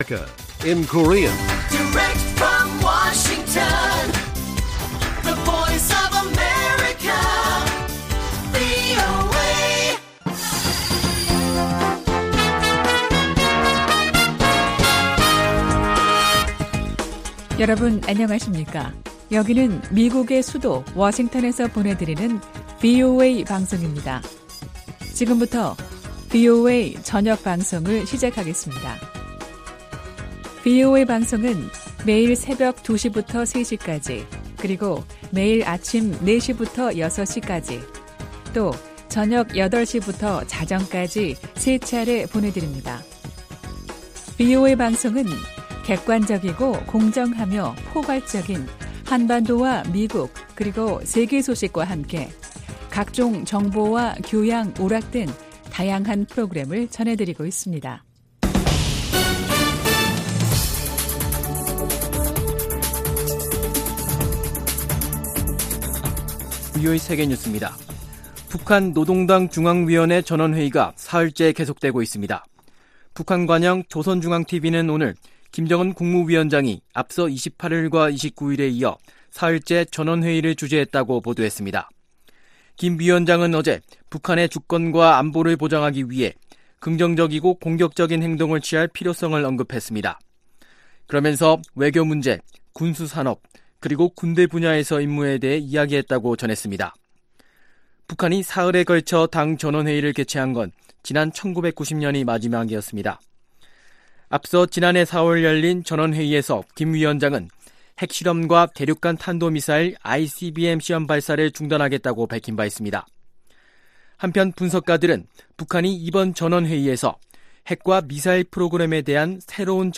VOA 한국어 간판 뉴스 프로그램 '뉴스 투데이', 2019년 12월 30일1부 방송입니다. 미국은 북한의 행동을 면밀히 주시하고 있으며, 일부 우려스러운 상황이 있다고 로버트 오브라이언 백악관 국가보좌관이 밝혔습니다. 미국 민주당 대선 후보 경선에 나선 주요 후보들은 대부분 북한 문제는 동맹과 외교를 통해 해결해야 한다는 견해를 밝혔습니다.